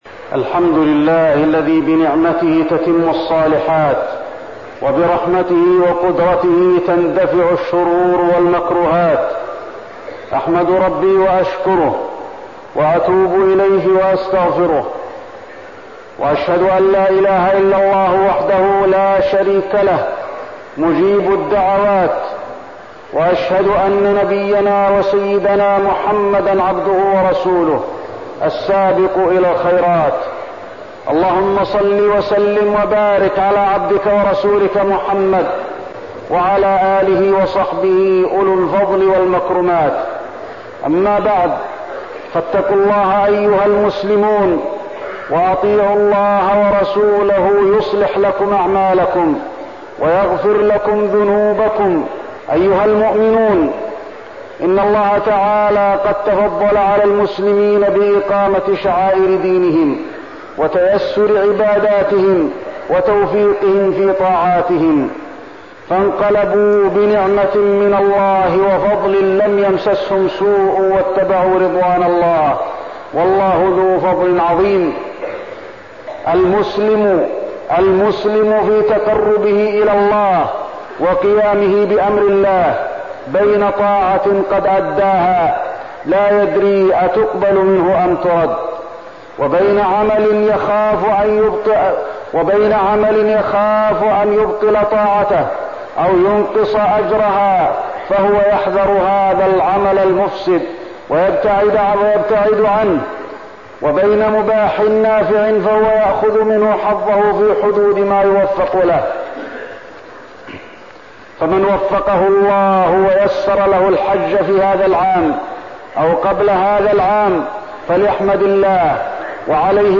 تاريخ النشر ١٧ ذو الحجة ١٤١٤ هـ المكان: المسجد النبوي الشيخ: فضيلة الشيخ د. علي بن عبدالرحمن الحذيفي فضيلة الشيخ د. علي بن عبدالرحمن الحذيفي التحصن من أعداء الله